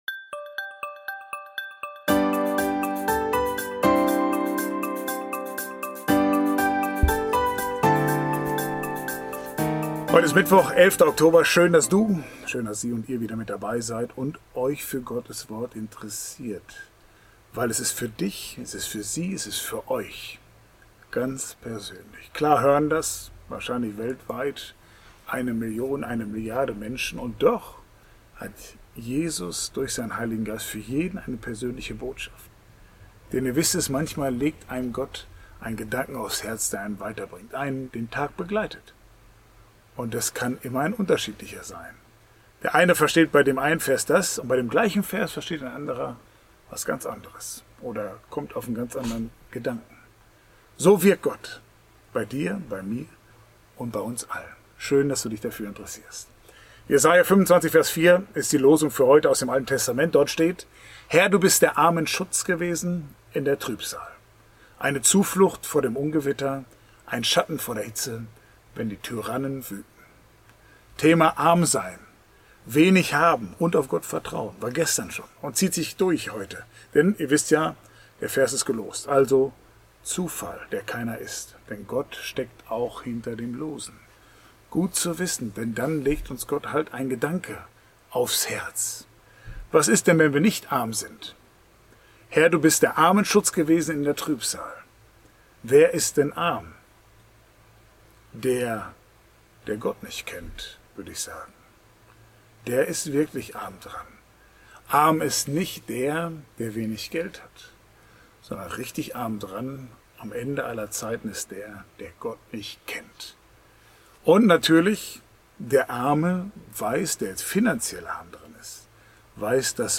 Ein täglicher Impuls